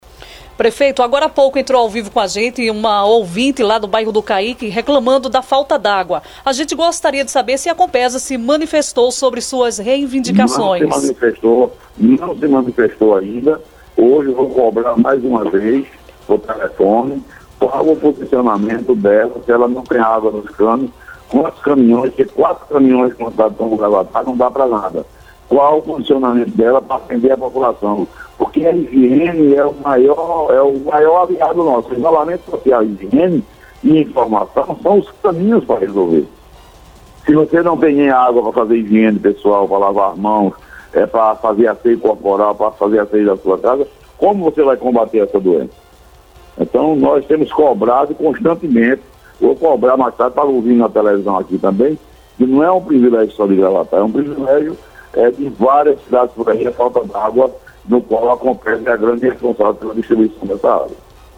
O prefeito de Gravatá, Joaquim Neto (PSDB) participou por telefone de entrevista na Rádio Clima FM, durante o programa Notícias da Clima.